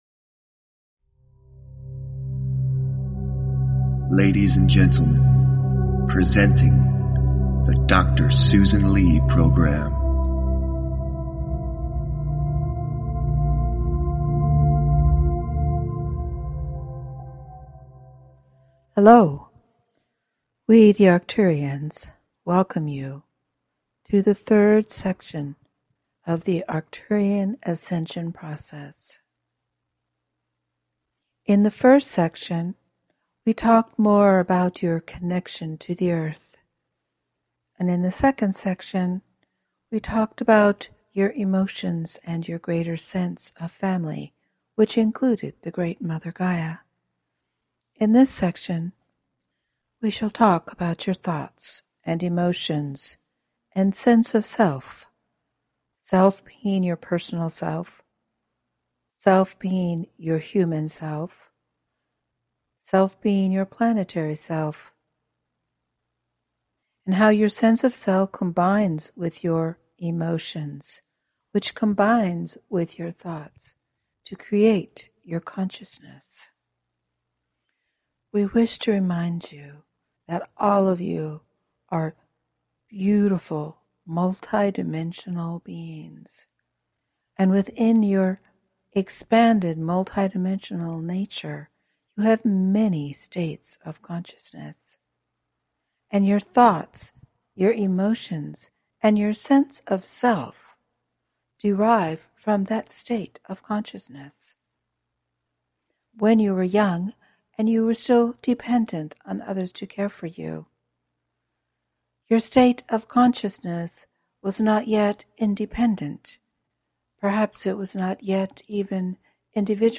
Arcturian guided meditation and Healing the Self, Healing the Mother Part 3